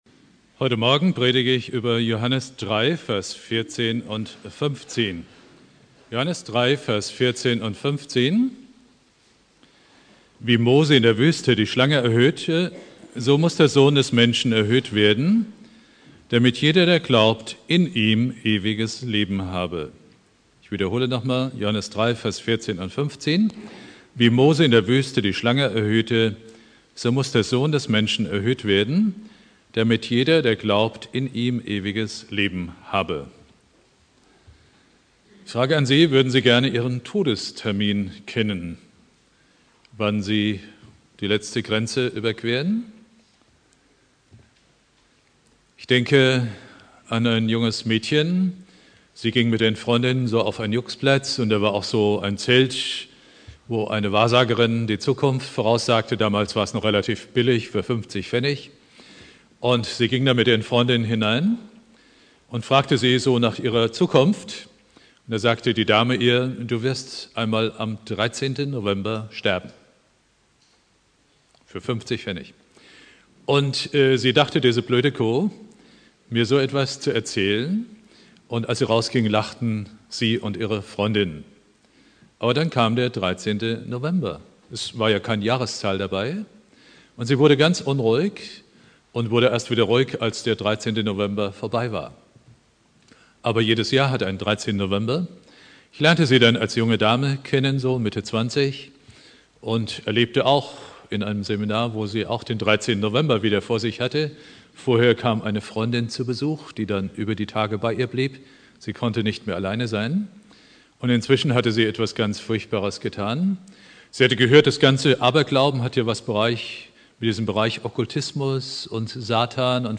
Predigt
Karfreitag